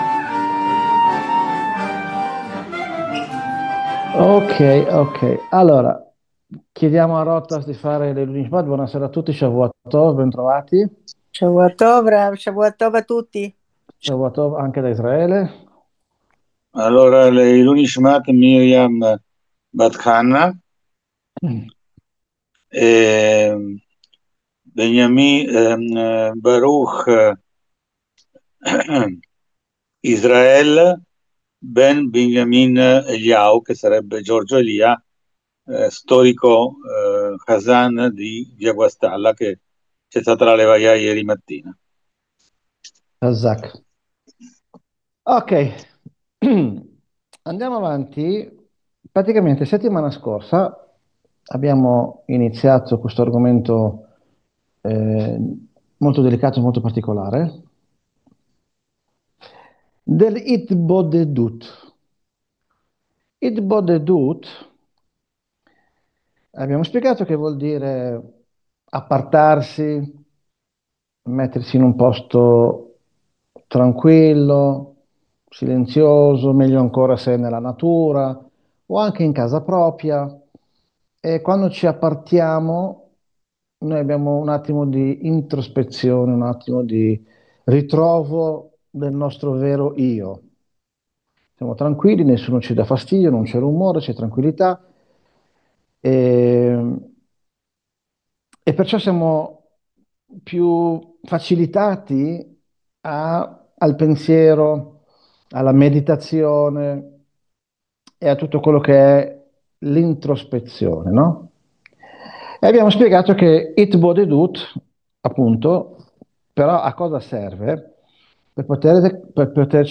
Lezione del 1 novembre 2025